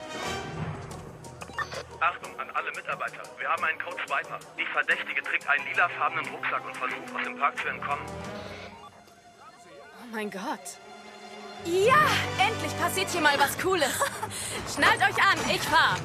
sehr variabel, hell, fein, zart
Jung (18-30)
Lip-Sync (Synchron)